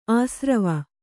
♪ āsrava